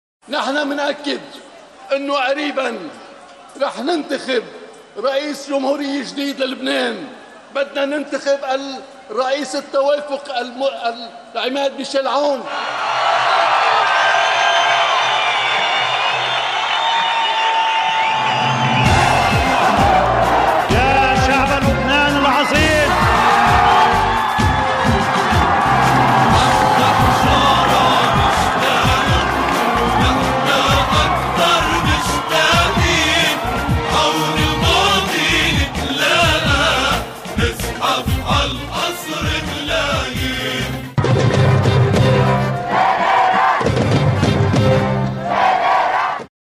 الجدير بالذكر أنّ الرئيس أمين الجميل كان قد أخطأ في إحدى خطبه أمام جمهور 14 آذار.. فبدل أن يُقول الرئيس التوافقي العماد ميشال سليمان قال الرئيس التوافقي العماد ميشال عون…